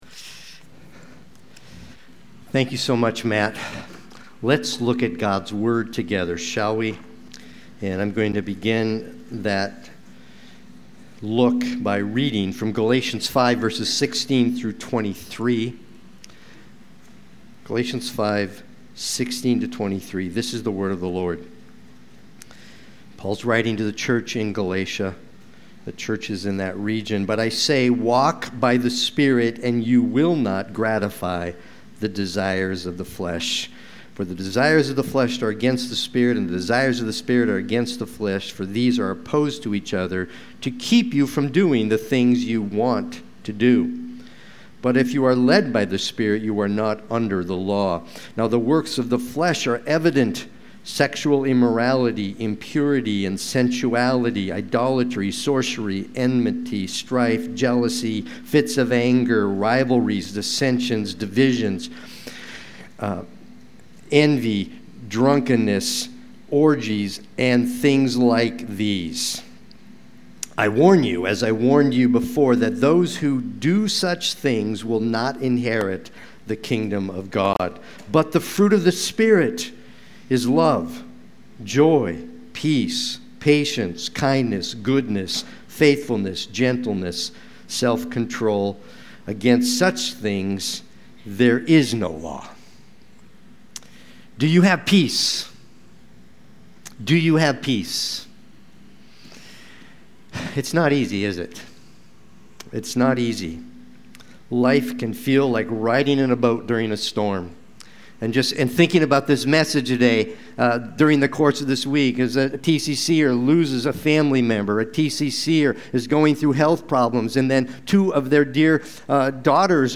Sunday-Worship-main-63024.mp3